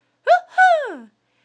a_cheer2.wav